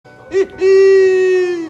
Play, download and share HEEHEE original sound button!!!!